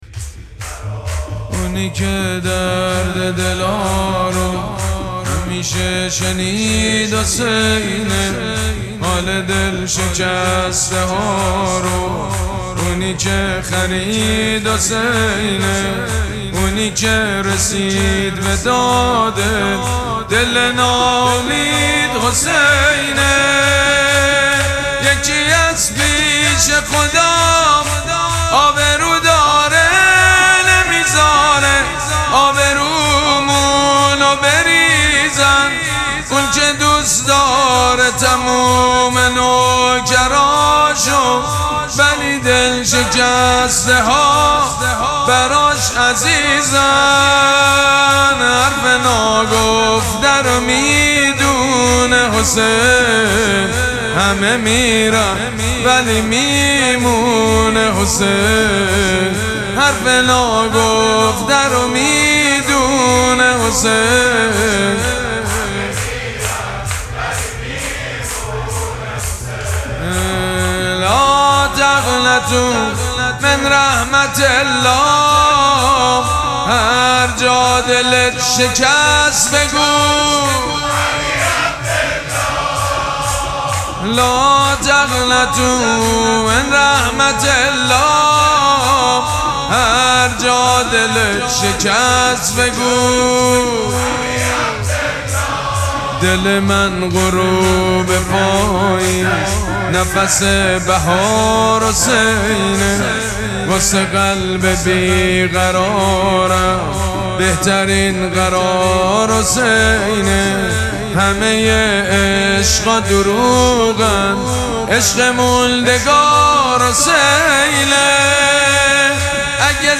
مراسم مناجات شب بیستم ماه مبارک رمضان
حسینیه ریحانه الحسین سلام الله علیها
شور